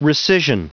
Prononciation du mot rescission en anglais (fichier audio)
Prononciation du mot : rescission